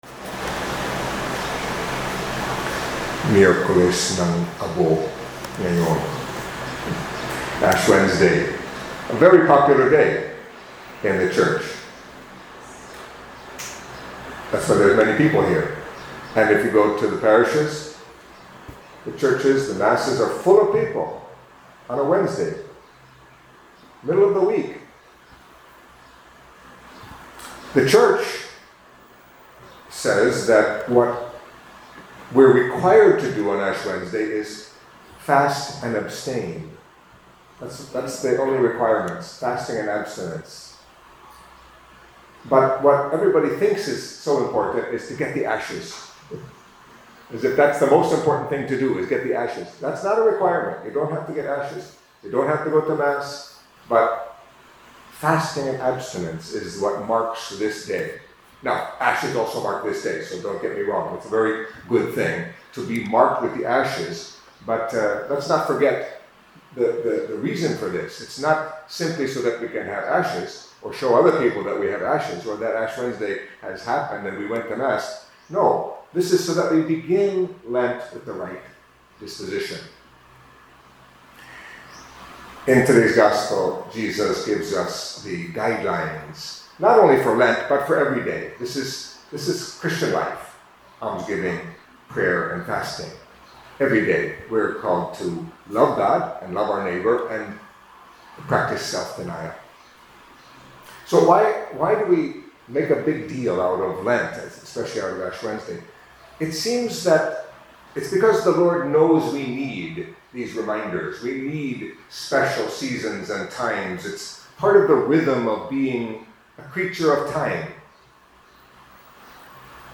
Catholic Mass homily for Ash Wednesday